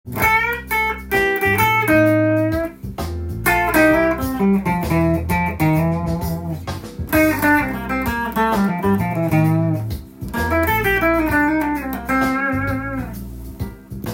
Cマイナーペンタトニックスケールを混ぜて弾いています。
フレーズを使いながらアドリブで弾いてみました